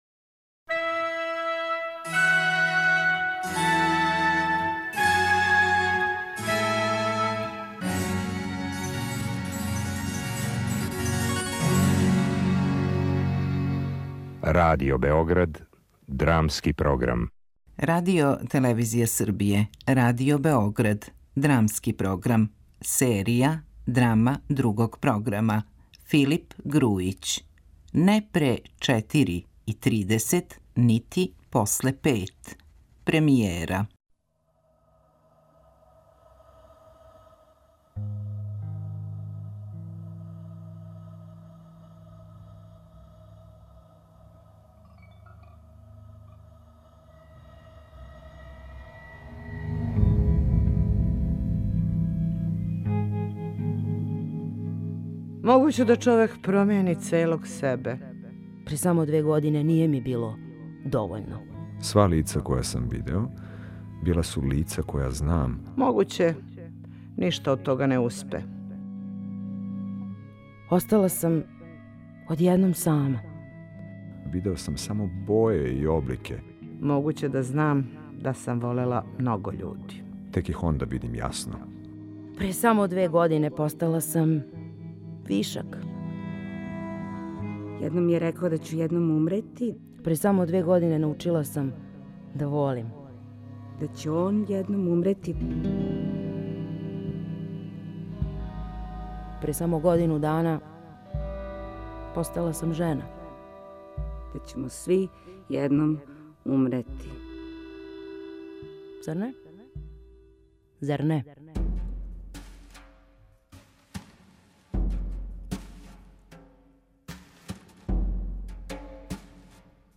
Драма Другог програма (премијера)